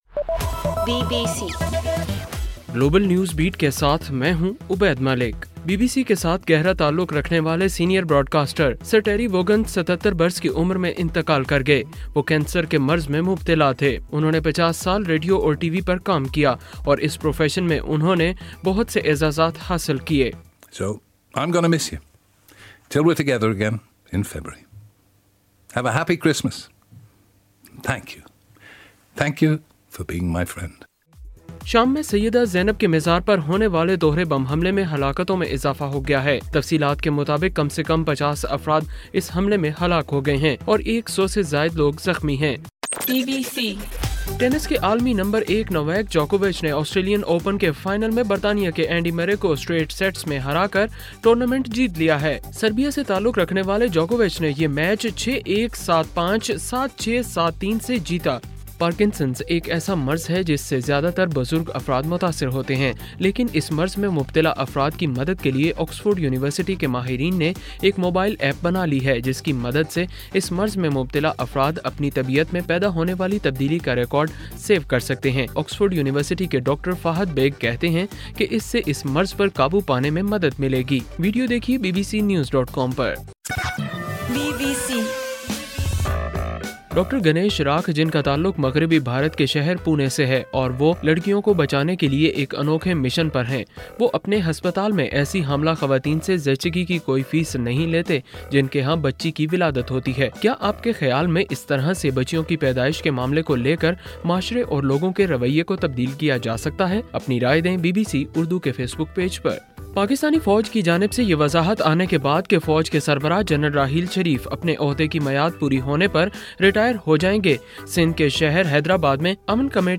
یکم فروری : صبح 1 بجے کا گلوبل نیوز بیٹ بُلیٹن